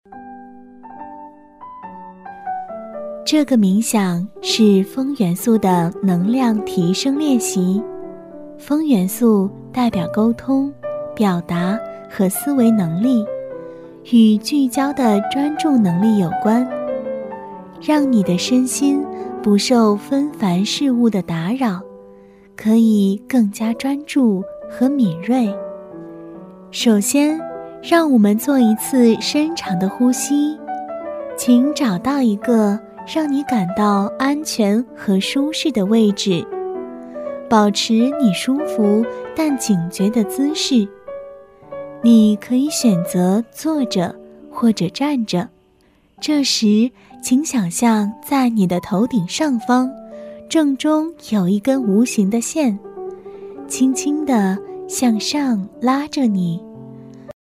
女C3-瑜伽冥想-舒缓
女C3-百变女王 年轻舒缓
女C3-瑜伽冥想-舒缓.mp3